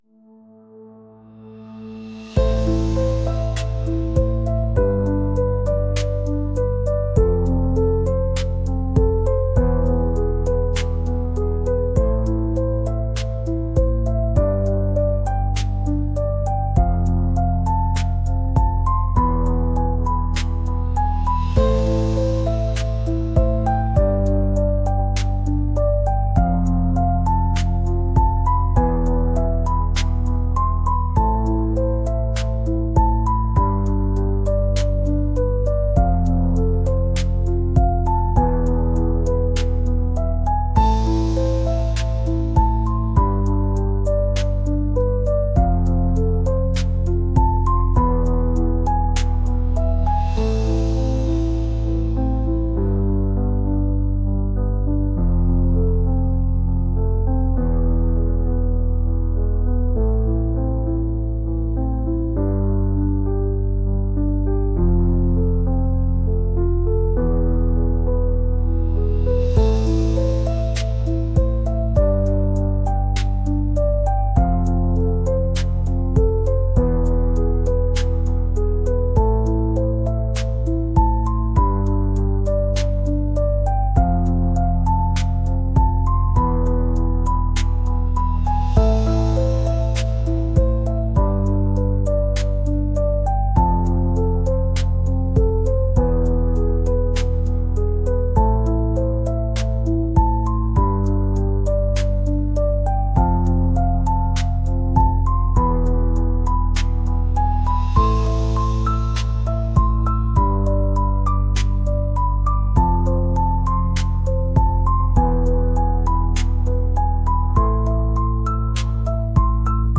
pop | ambient | cinematic